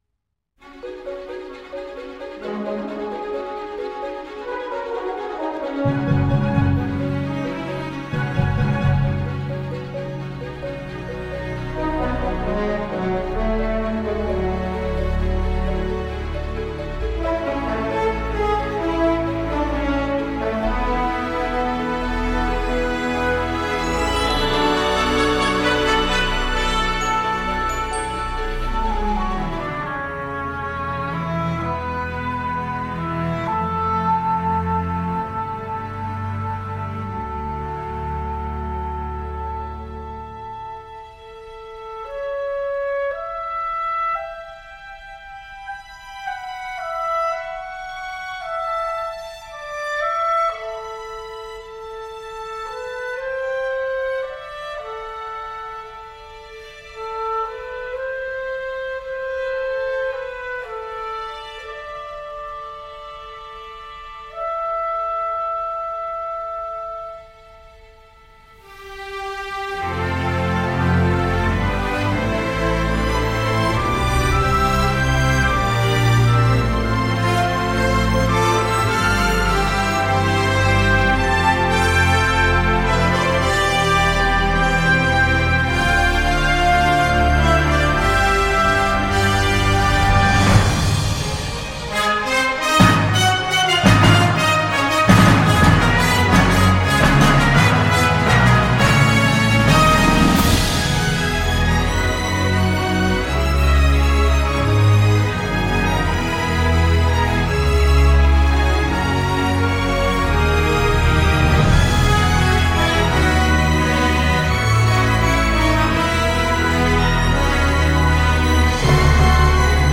musique orchestrale fraîche, bondissante et euphorisante